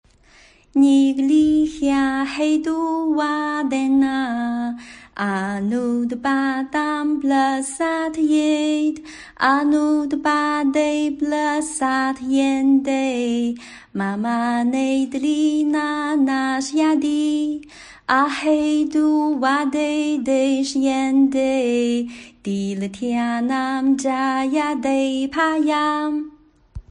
楞伽经3.91念诵.mp3.ogg